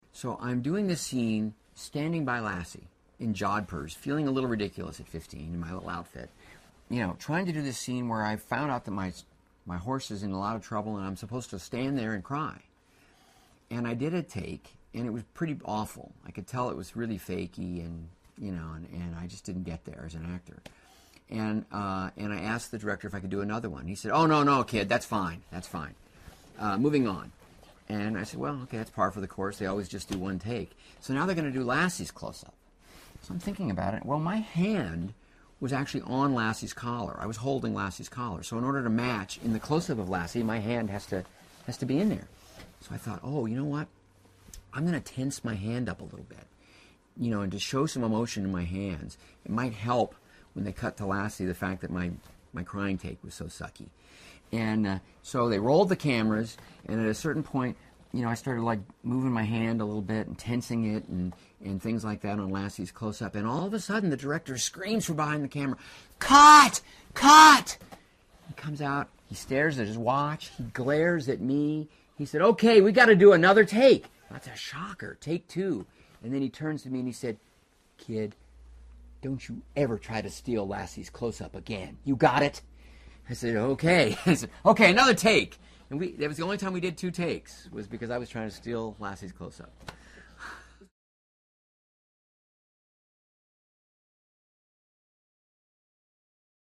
访谈录 2012-08-25&08-27 资深电影人罗恩.霍华德专访 听力文件下载—在线英语听力室